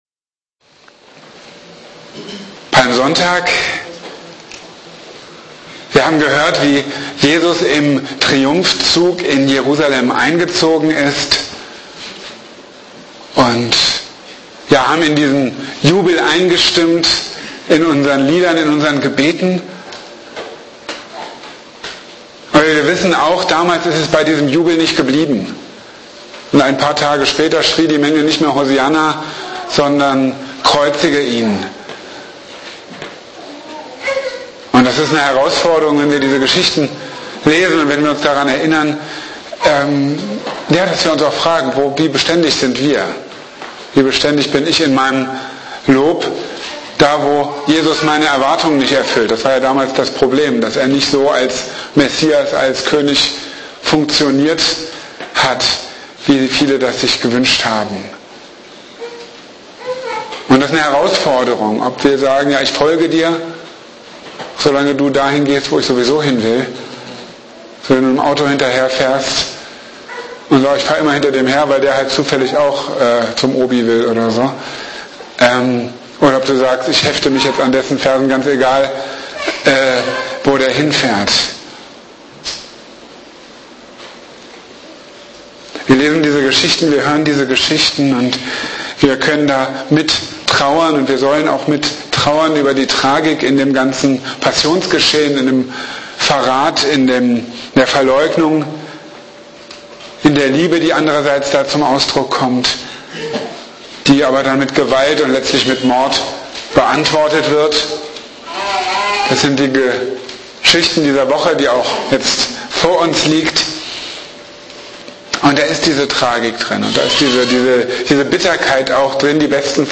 Marburger Predigten
An diesem Sonntag hat die Anskar-Kirche Marburg übrigens ihren 7. Gemeinde-Geburtstag gefeiert!